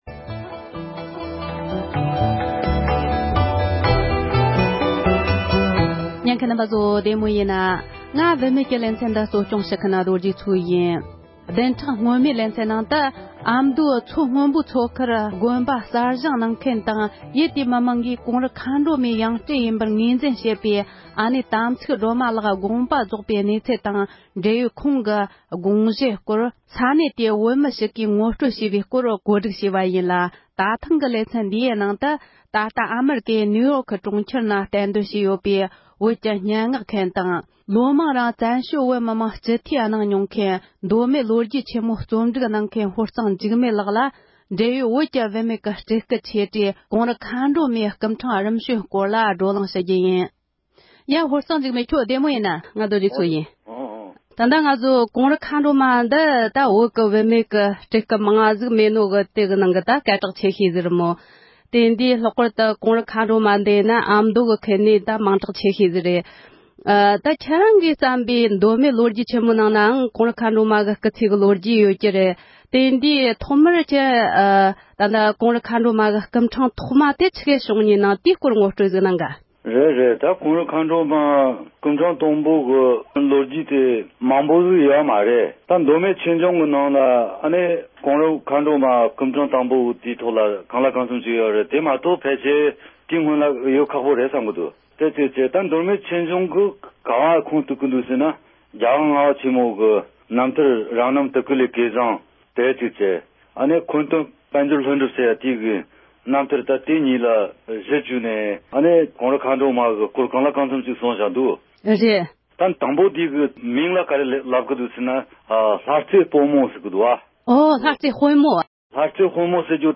བཀའ་འདྲི་ཞུས་པར་གསན་རོགས་གནོངས༎